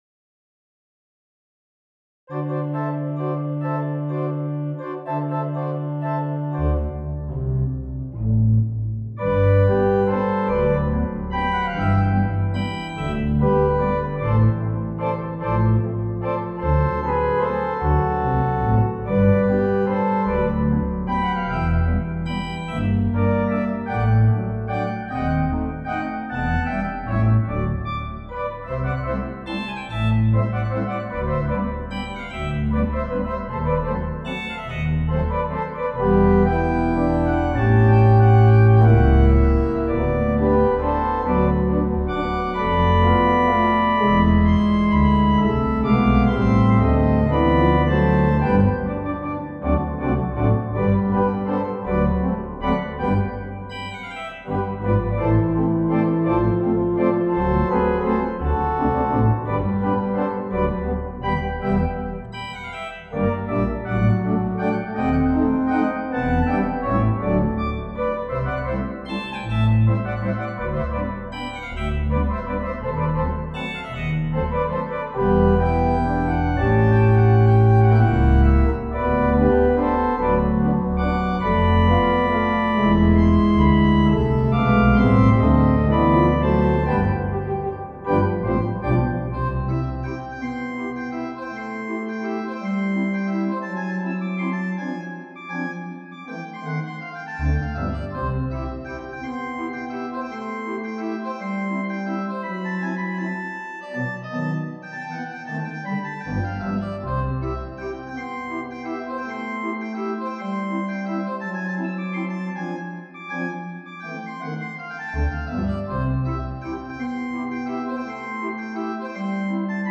Music recorded on concert organs
HW: Custom Pasadena Presb